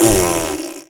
spit.wav